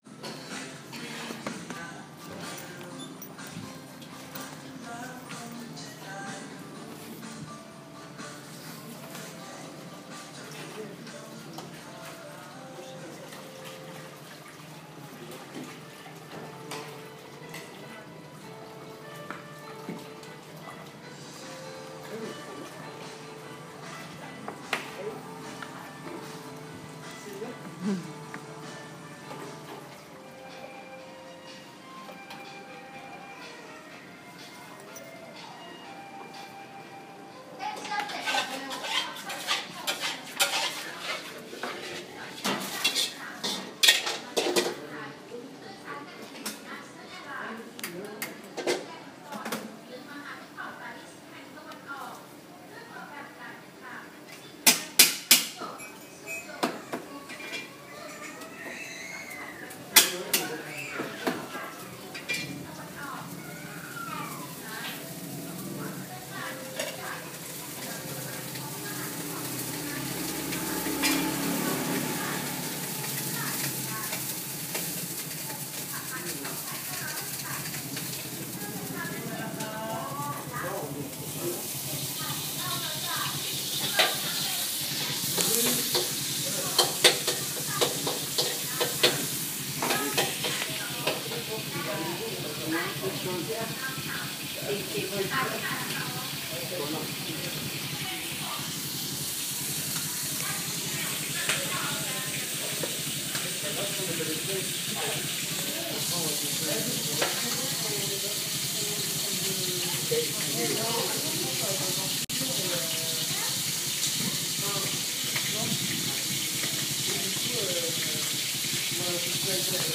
지글지글. 할머니식당
길지도 그러나 짧지도 않은 시간을 치앙마이에서 보내는 동안 치앙마이에서만 들을 수 있는, 그 순간만 들을 수 있는 소리를 채집했습니다.
할머니의 공간에서 나던 지글지글 소리를 들으며 누워있자니 사방이 그곳이다.